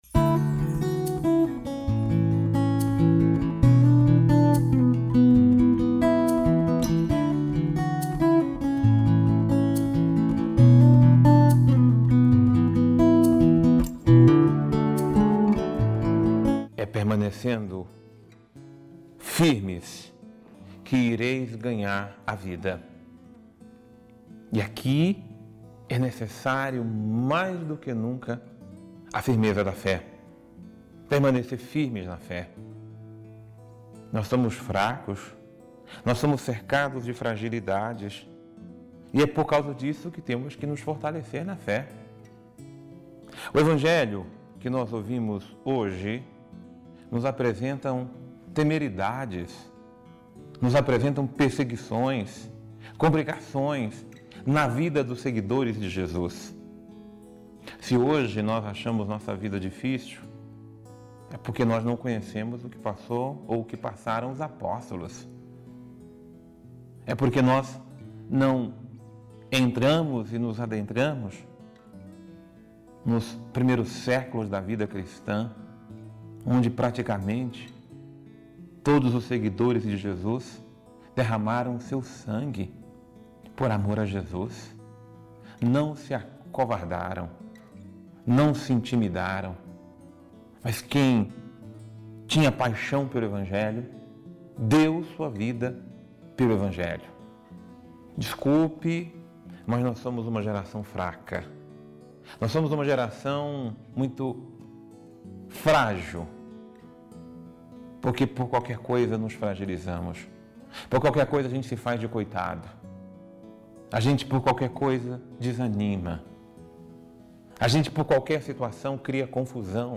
Homilia | É necessário fortalecermos a nossa fé em Jesus